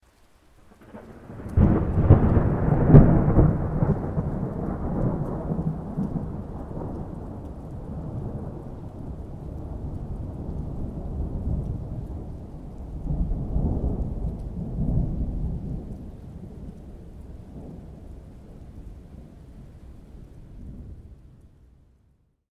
thunder_4.ogg